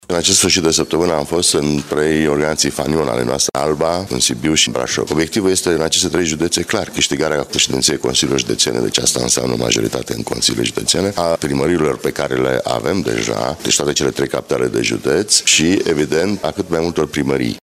Organizația PNL Brașov este una dintre cele mai bune din țară, spune copreşedintele PNL Vasile Blaga, prezent la evenimentul de la Brașov.